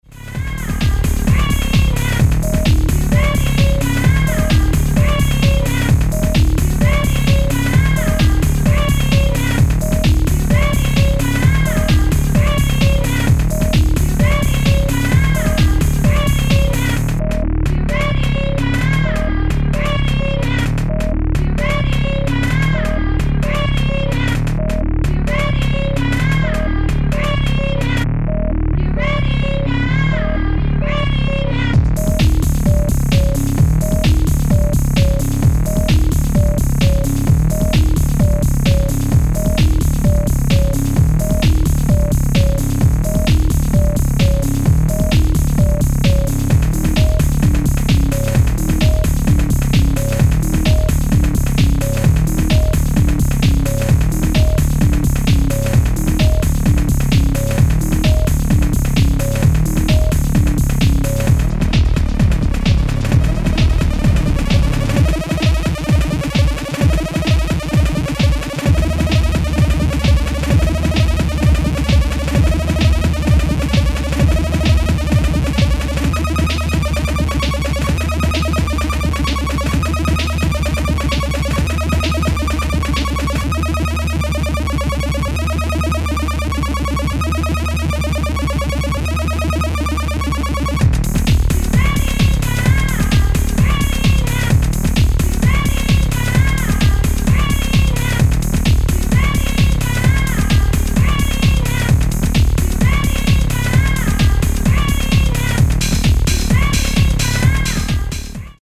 i like this a lot, really funky stuff there mate.